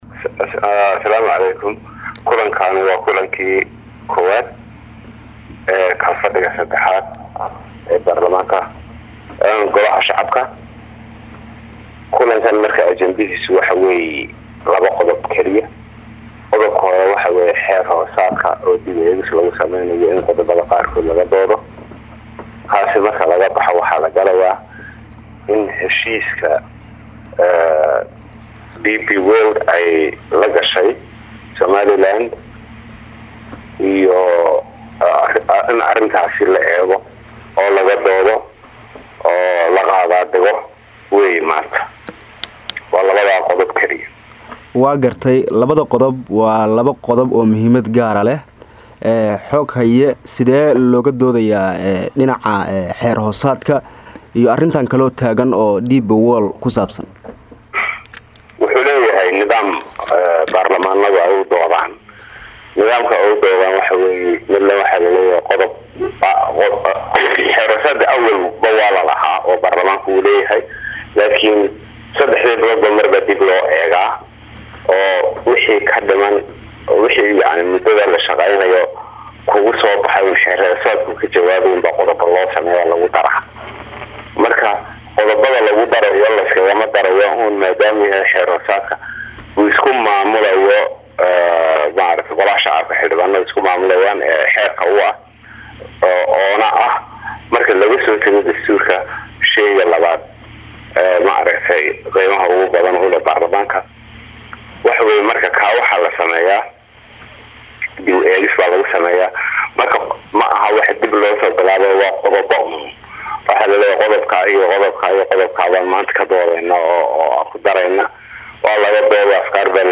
Halkaan ka dhageyso Wareysi aan la yeelanaya Xoghaayaha Guud ee Golaha Shacabka Mudane C/kariin Xaaji Cabdi Buux